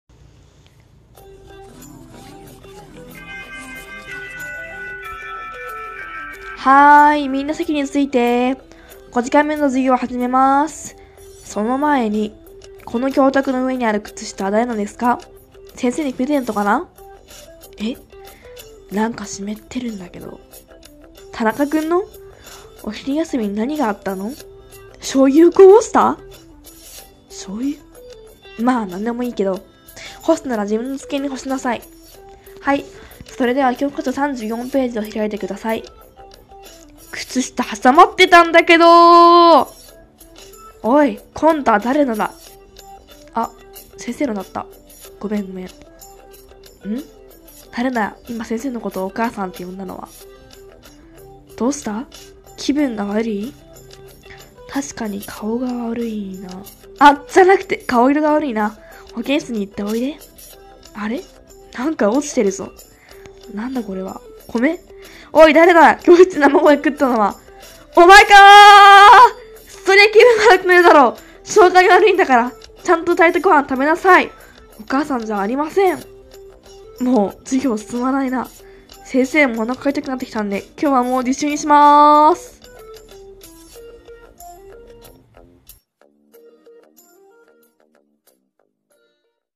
ひとり声劇『授業が進まない』